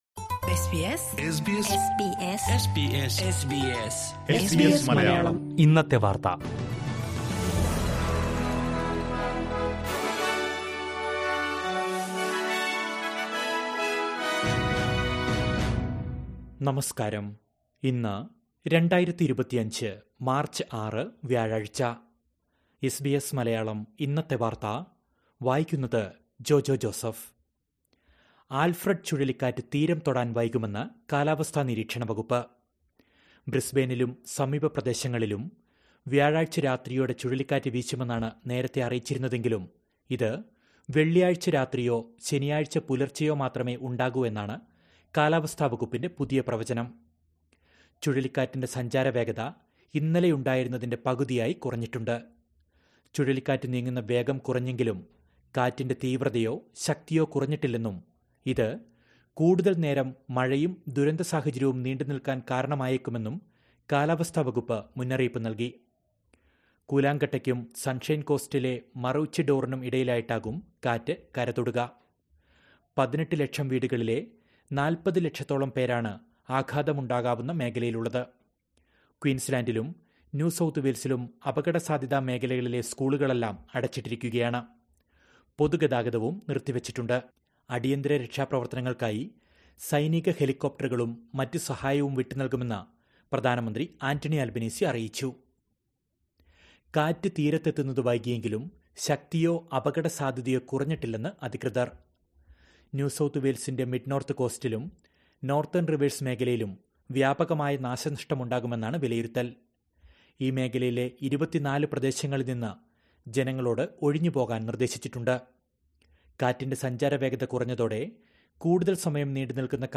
2025 മാര്‍ച്ച് ആറിലെ ഓസ്‌ട്രേലിയയിലെ ഏറ്റവും പ്രധാന വാര്‍ത്തകള്‍ കേള്‍ക്കാം